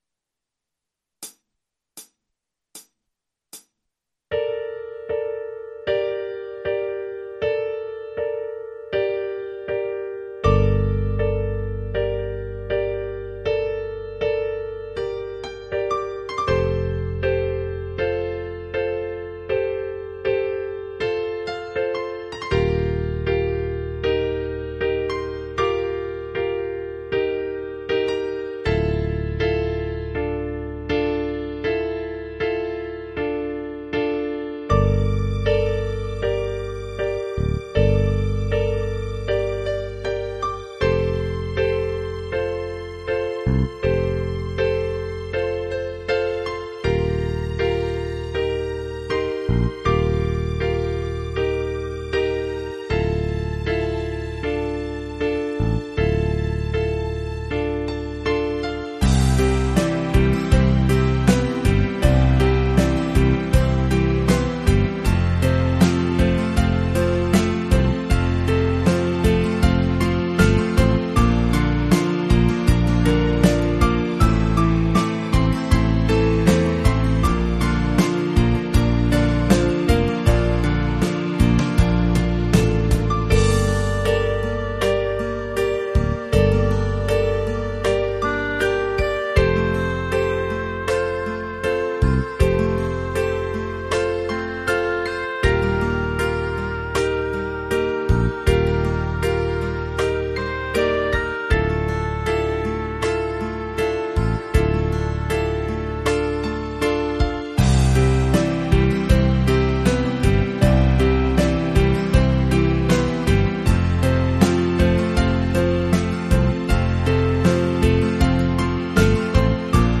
multi-track instrumentale versie